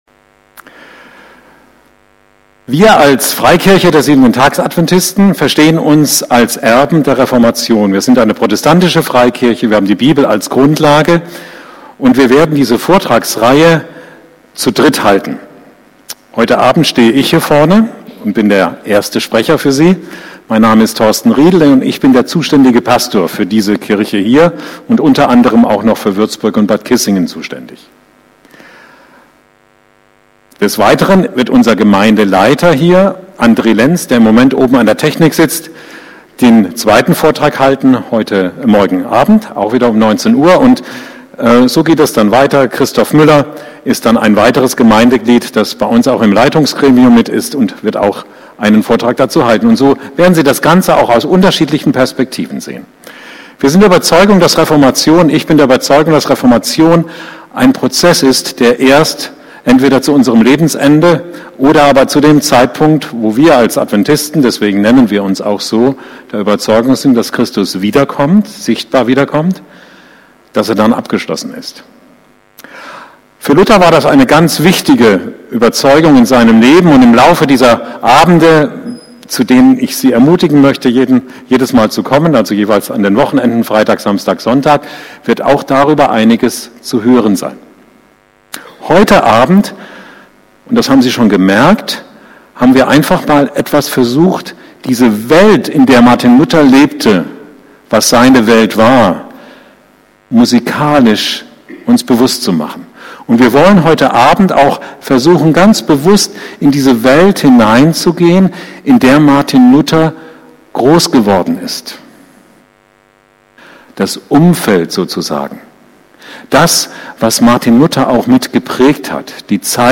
Vorträge